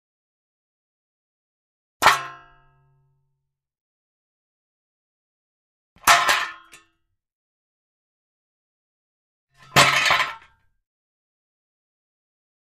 ShovelMetalDropsX3 TE35329
Shovel, Metal, Drops x3